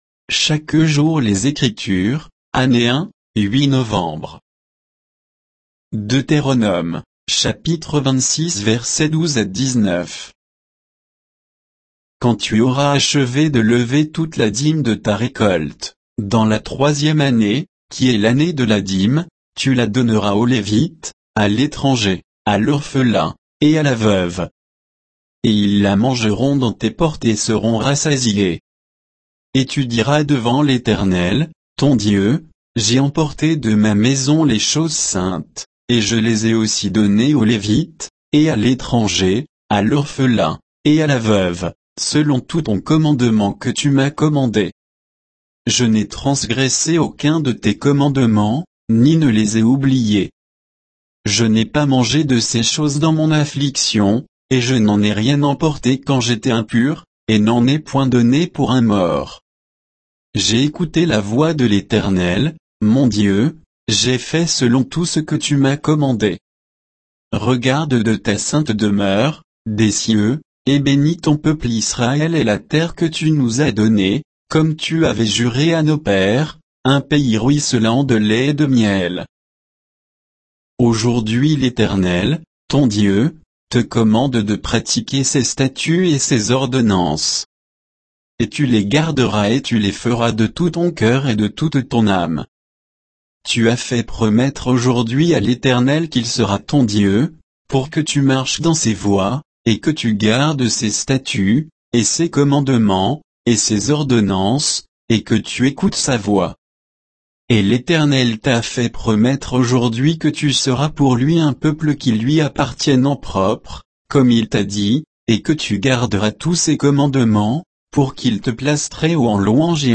Méditation quoditienne de Chaque jour les Écritures sur Deutéronome 26